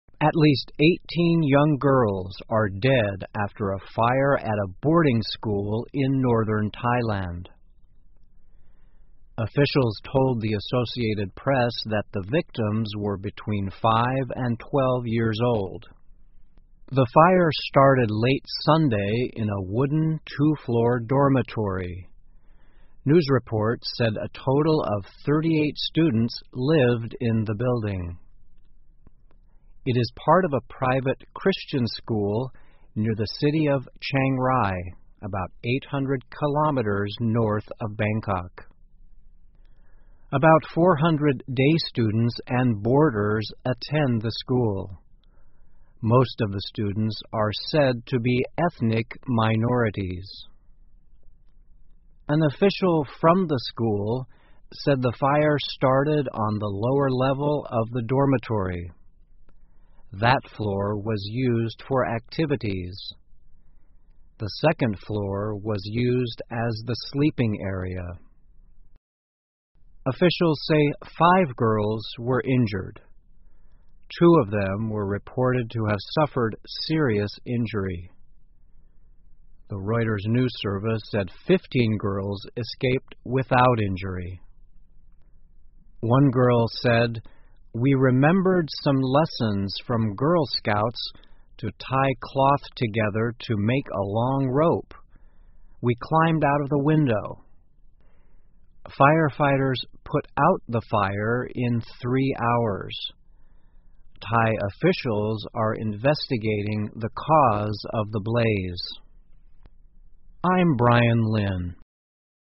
VOA慢速英语--泰国学校发生火灾 超过18名女孩死亡 听力文件下载—在线英语听力室